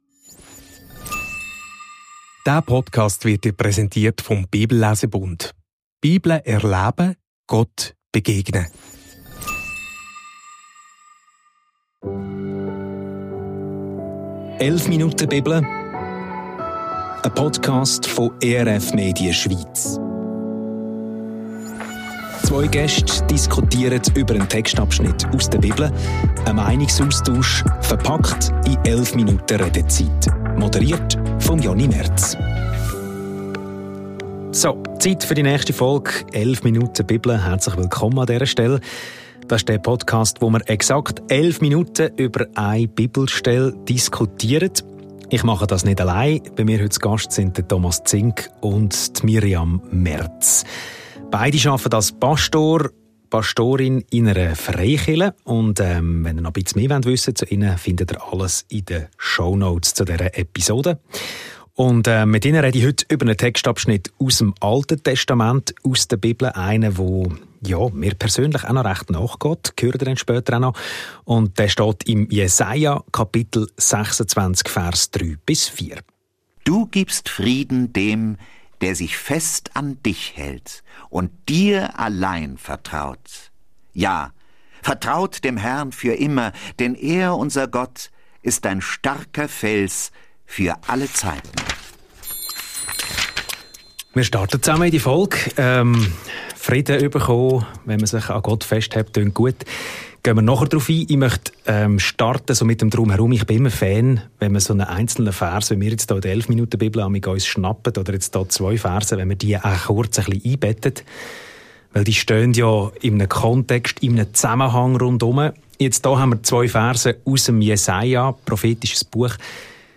Hält mich Gott, auch wenn ich ihn nicht spüre? – Jesaja 26,3–4 ~ 11 Minuten Bibel – ein Meinungsaustausch Podcast